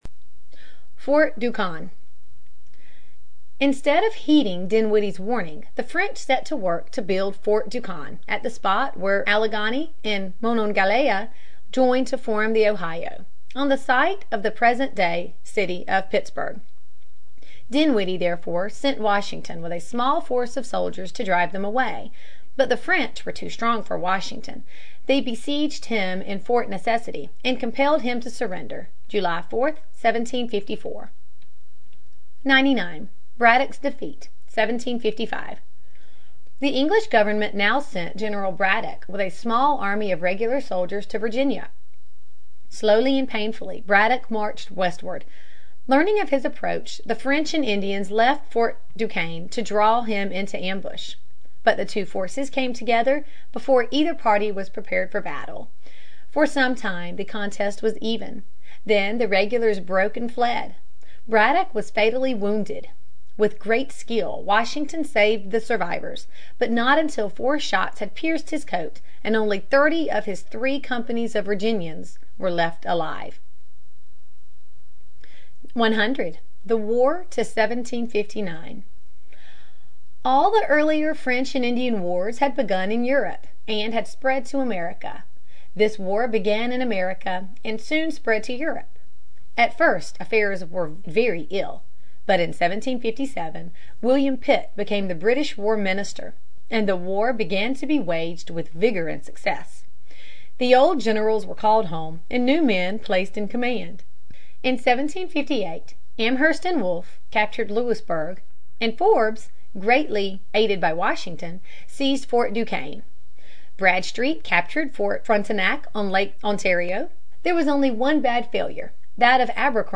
在线英语听力室美国学生历史 第32期:驱除法国人(4)的听力文件下载,这套书是一本很好的英语读本，采用双语形式，配合英文朗读，对提升英语水平一定更有帮助。